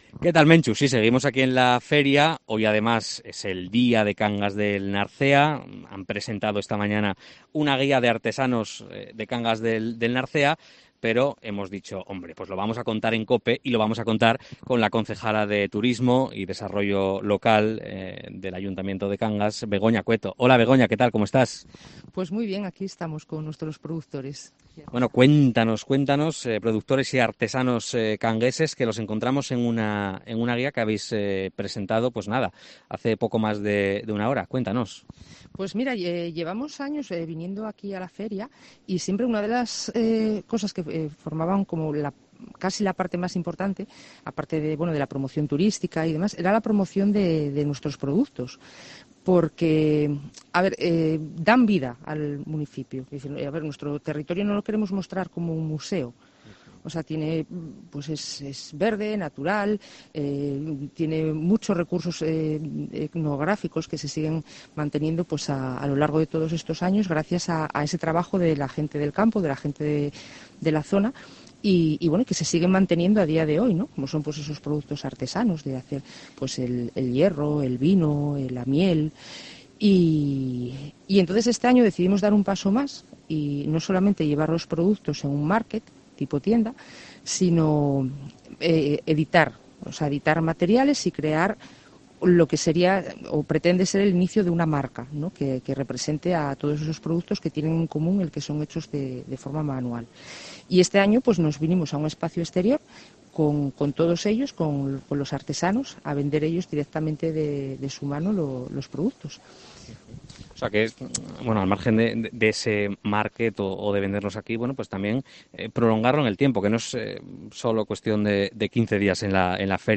COPE EN LA FIDMA
Entrevista a Begoña Cueto, concejala de Turismo del Ayuntamiento de Cangas